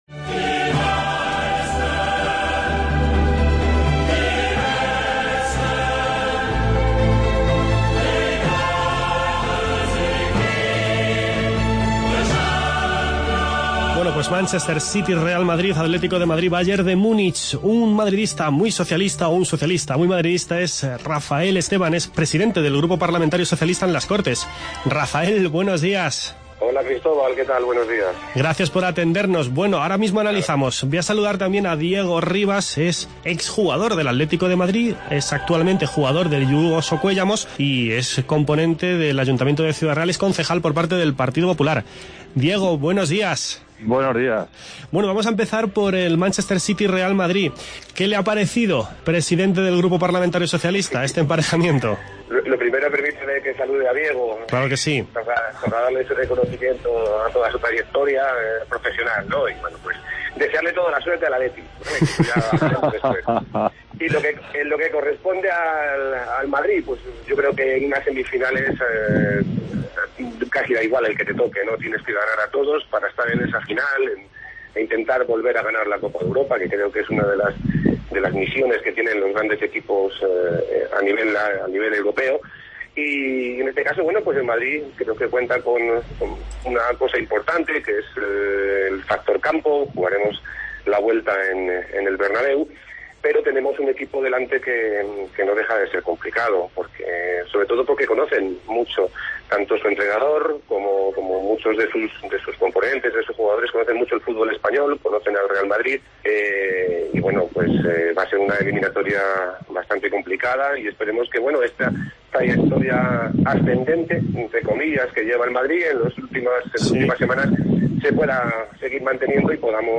Manchester City-Real Madrid y Atlético de Madrid-Bayern de Munich son los emparejamientos de las semifinales de la Champions. Hablamos de esta noticia de última hora con Rafael Esteban, madridista y presidente del Grupo Parlamentario Socialista en las Cortes de Castilla-La Mancha, y con Diego Rivas, colchonero y concejal del Partido Popular en el Ayuntamiento de Ciudad Real.